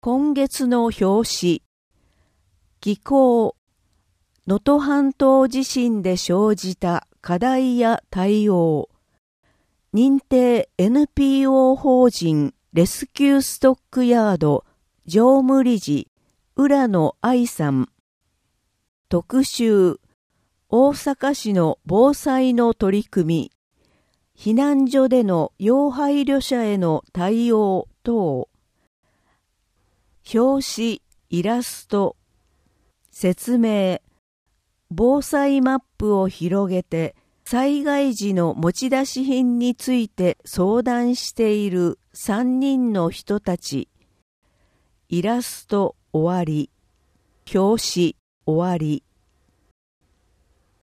本号につきましては、淀川区で活動されている「音訳ボランティアグループこもれび」の有志の皆様に作成いただきました。